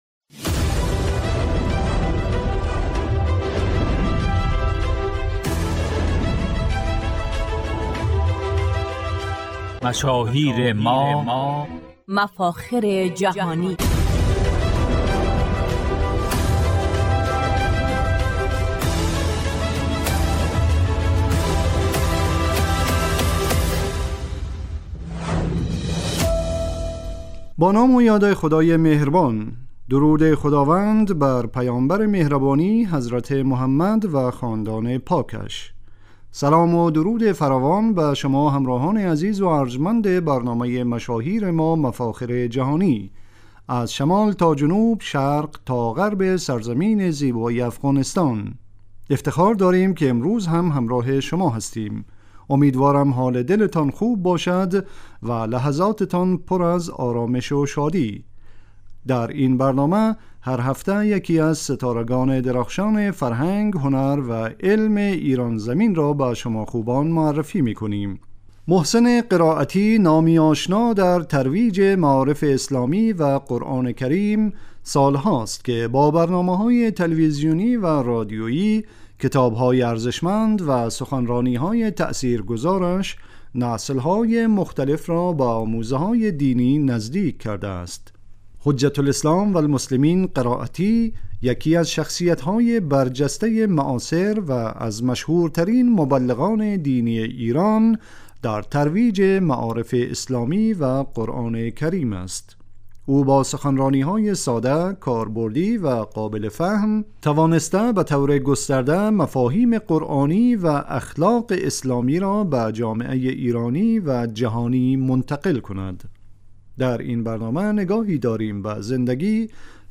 در این برنامه "حجت الاسلام قرائتی" یکی از مفاخر ایران زمین معرفی می شود. روزهای سه شنبه ساعت 15از رادیو دری شنونده این برنامه باشید...